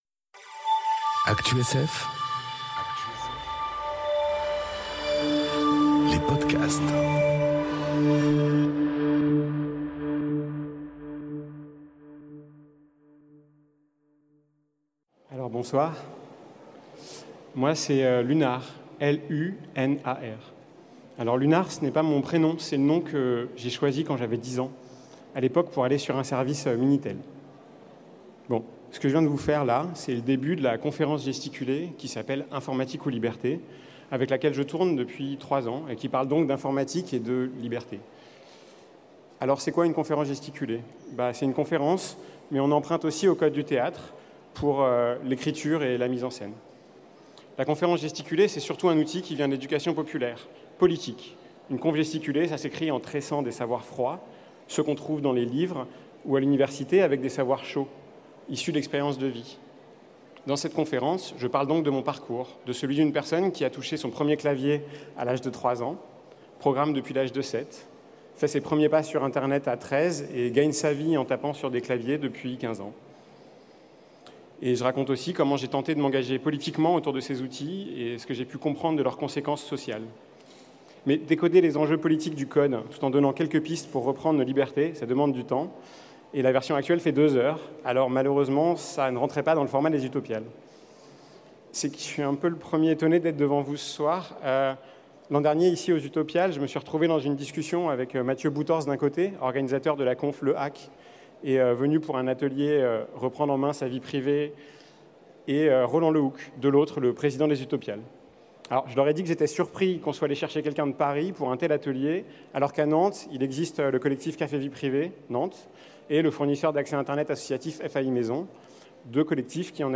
Utopiales 2019 - Informatique ou liberté ?